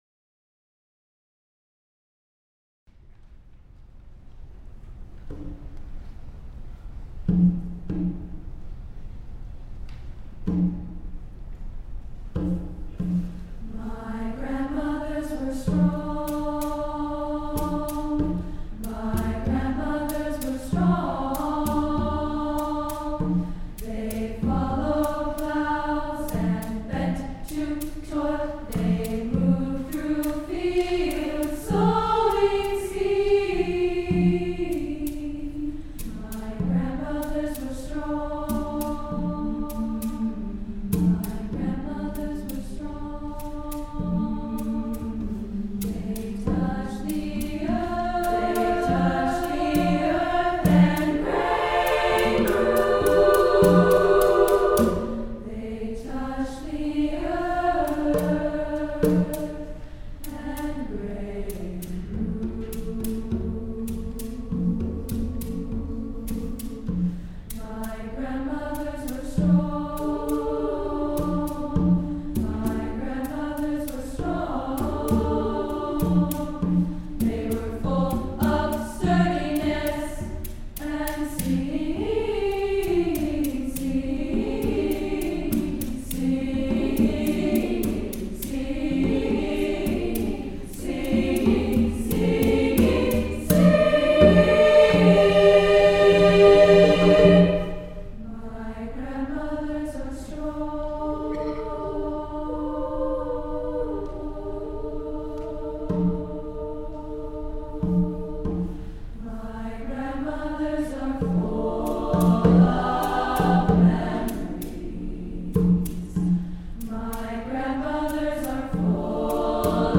The WSU Treble Choir is an ensemble comprised of students who sing either soprano or alto.
Recordings of Recent Performances